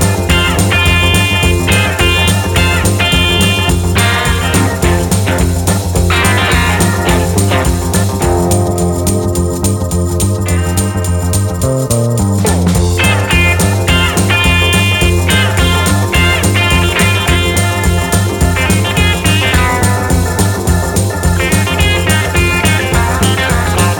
No Lead Guitar For Guitarists 2:42 Buy £1.50